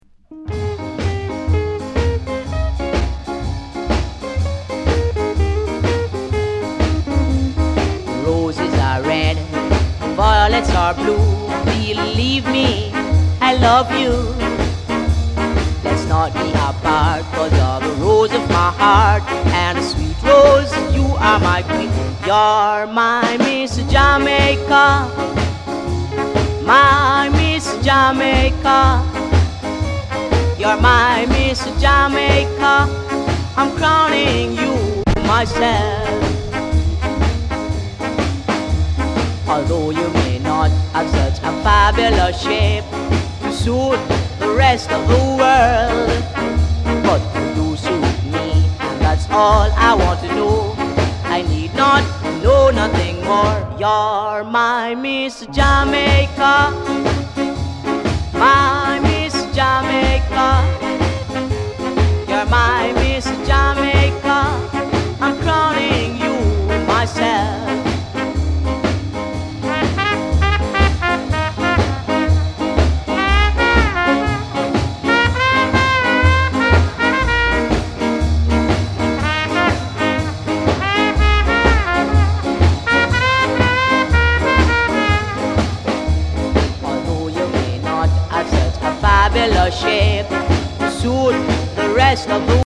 スカ・レゲエ
60年代中期のヴィンテージ感がたまらんです。